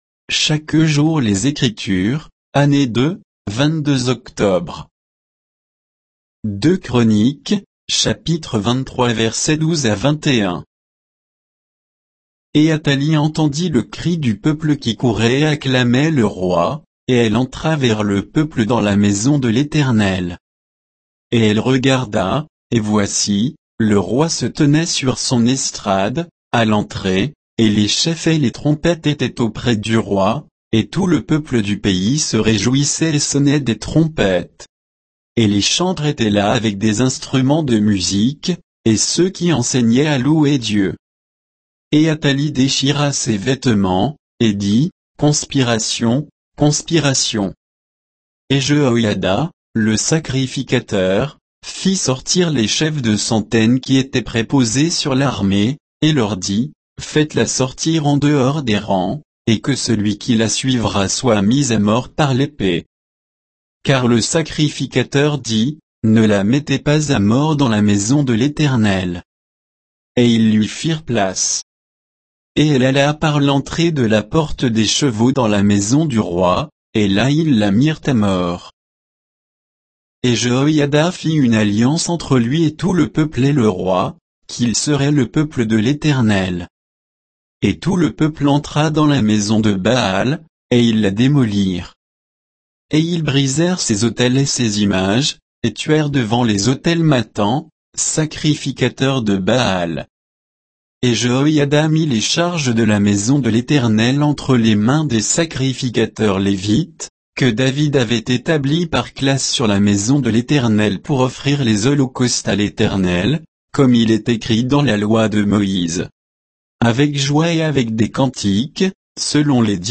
Méditation quoditienne de Chaque jour les Écritures sur 2 Chroniques 23, 12 à 21